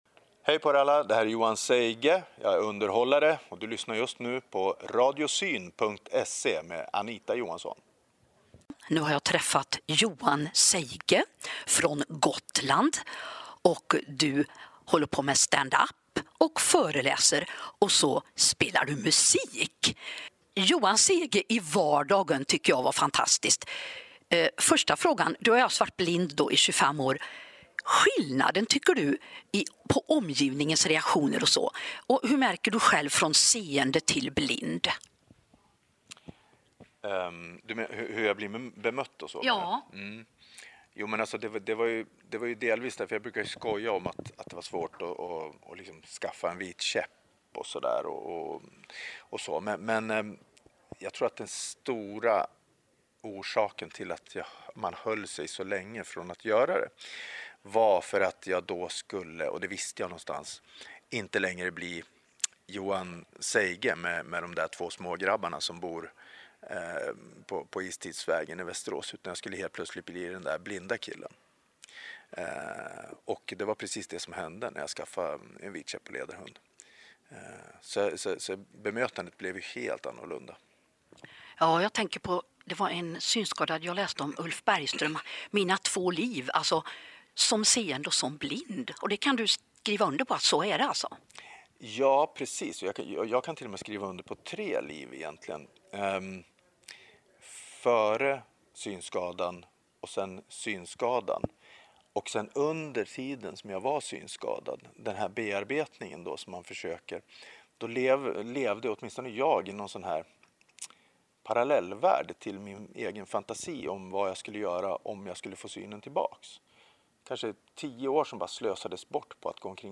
Tre foton på oss under intervjun